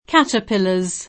caterpillar [ingl. ^të pilë] s. m. (in it.); pl. (ingl.) caterpillars [
^të pilë@] — spesso italianizz. nella pn. [katerp&llar], e in tal caso invariabile — nome depositato (propr. «bruco», per la somiglianza del modo d’avanzare di questi veicoli e di quegli animali)